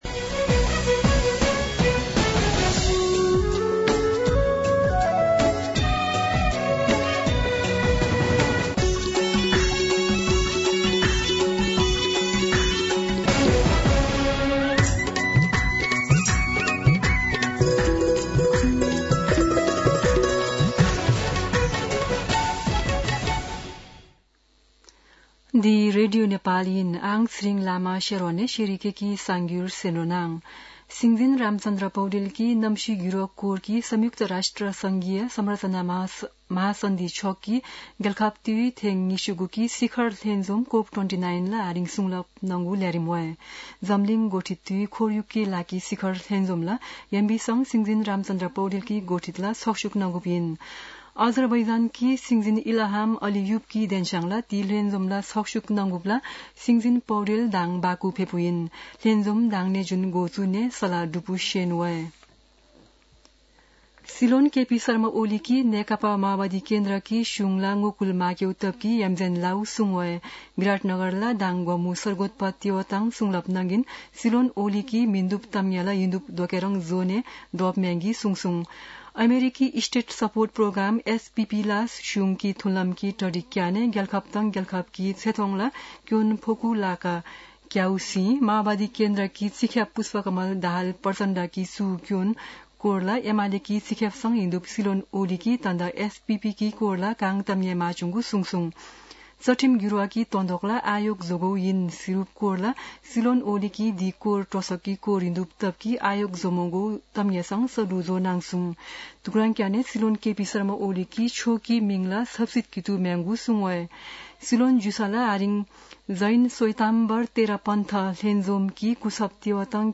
शेर्पा भाषाको समाचार : २८ कार्तिक , २०८१
Sherpa-News-27.mp3